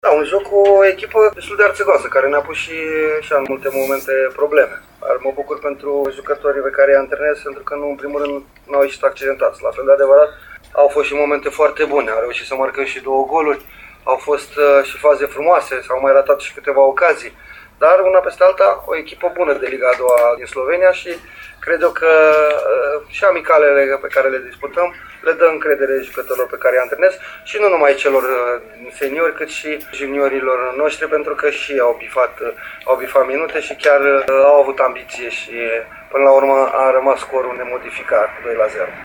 La final, tehnicianul s-a declarant mulțumit: